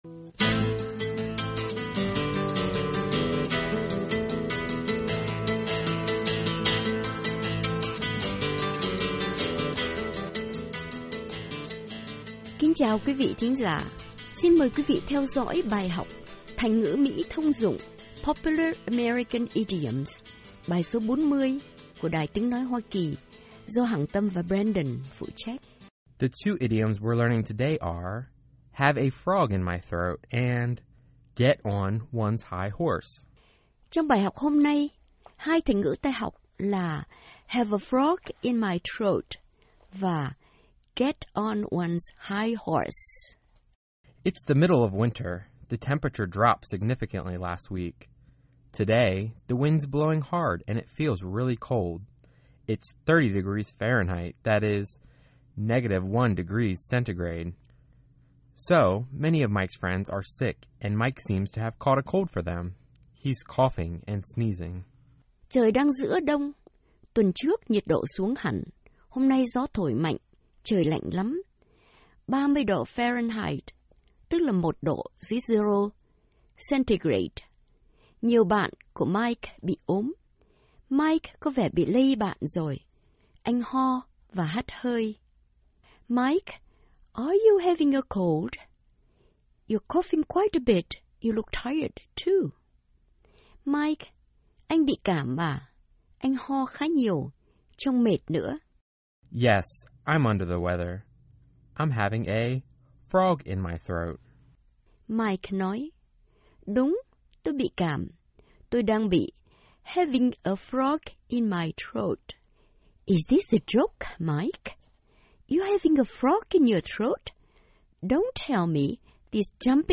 Xin mời quý vị theo dõi bài học THÀNH NGỮ MỸ THÔNG DỤNG “POPULAR AMERICAN IDIOMS” bài số 40 của đài Tiếng Nói Hoa Kỳ